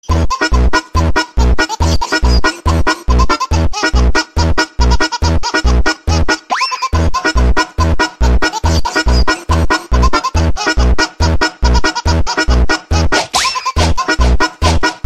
baby laugh phonk jersey Meme Sound Effect
baby laugh phonk jersey.mp3